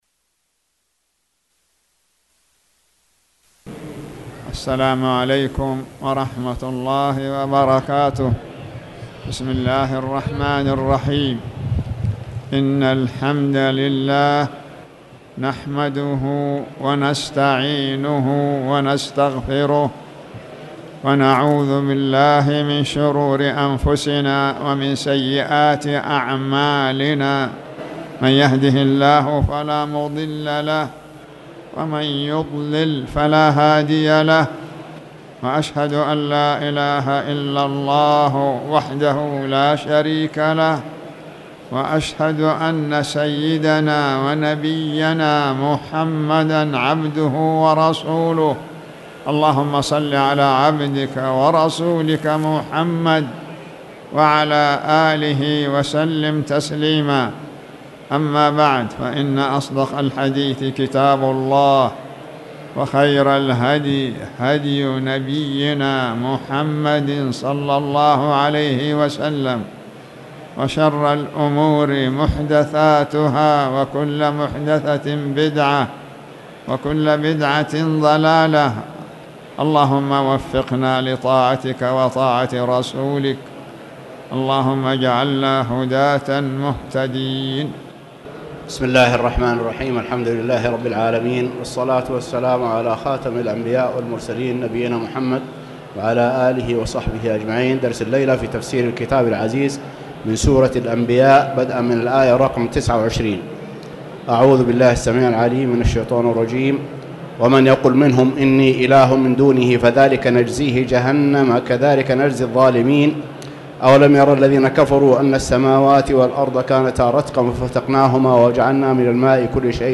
تاريخ النشر ١٠ ذو القعدة ١٤٣٨ هـ المكان: المسجد الحرام الشيخ